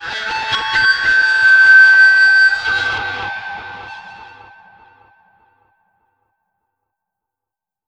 Index of /90_sSampleCDs/Spectrasonics - Bizarre Guitar/Partition F/10 FEEDBACK